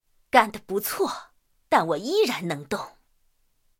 IS-7中破语音.OGG